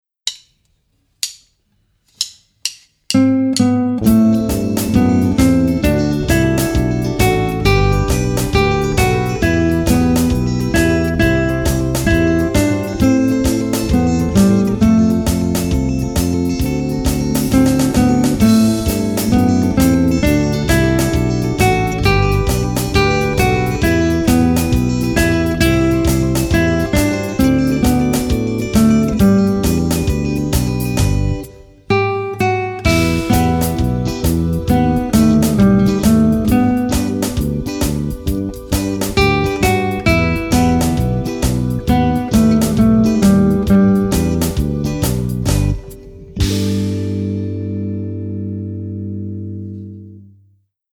bakgrunder